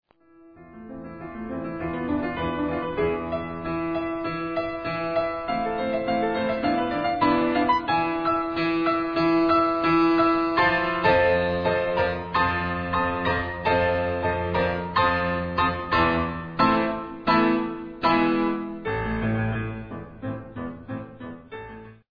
Franz Schubert, Klaviersonate a-Moll, D845, erster Satz "Moderato", T. 12 bis 29
Letzterer wird dann über eine Vorhaltkadenz nach E-Dur und endlich weiter in die Tonika a-Moll geführt.